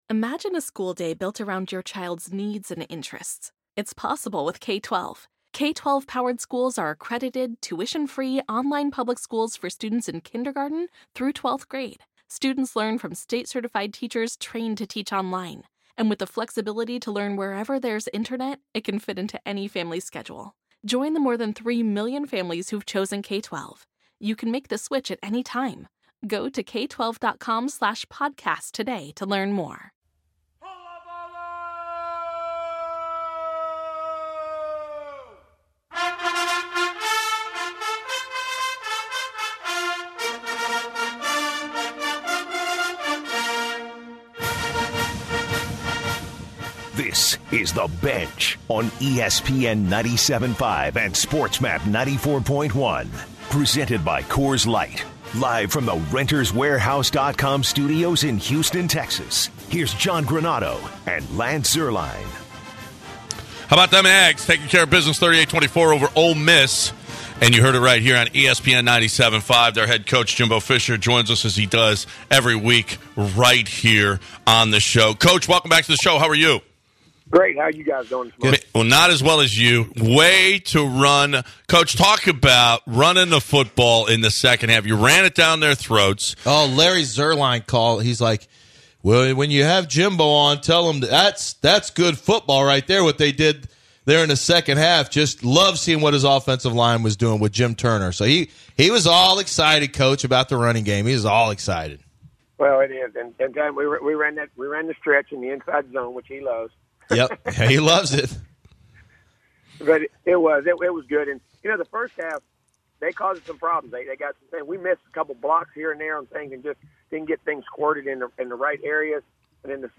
11/13/2018 The Bench interview TAMU head football coach Jimbo Fisher